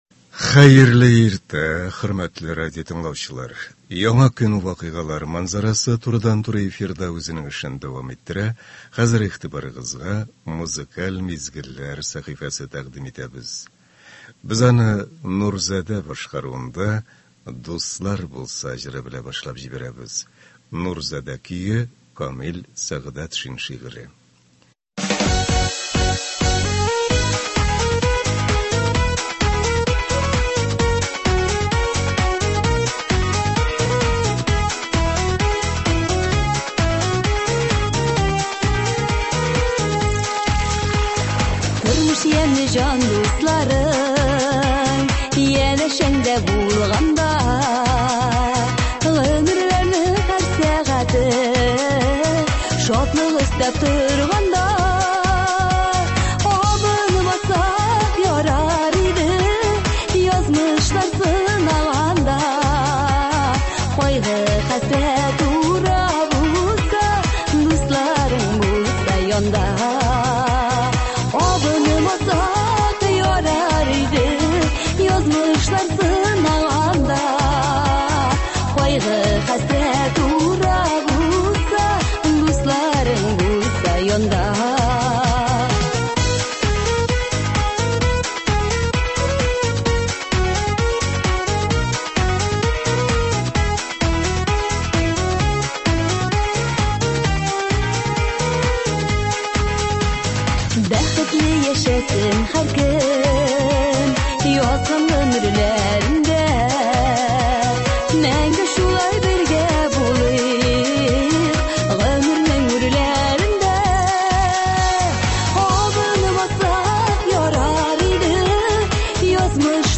Концерт.